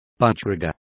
Complete with pronunciation of the translation of budgerigar.